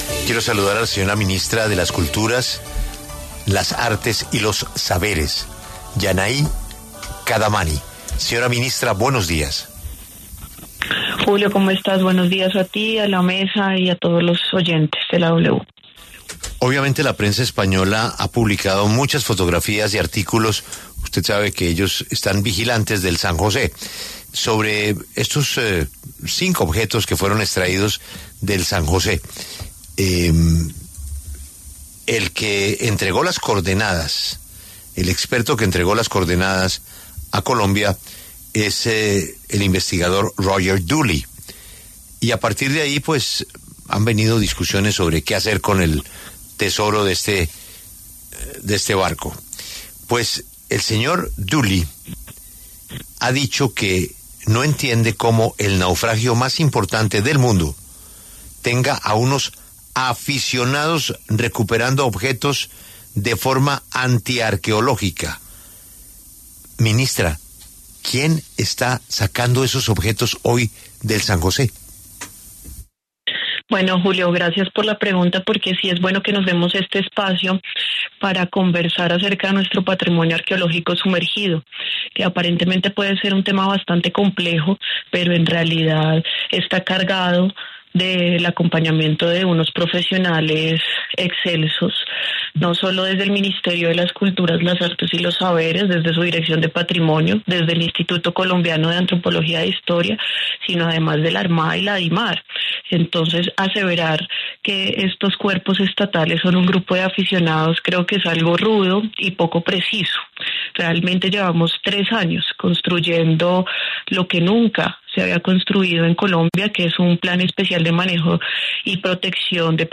Así, la ministra de Cultura, Yannai Kadamani, habló en La W, con Julio Sánchez Cristo, y respondió a las críticas.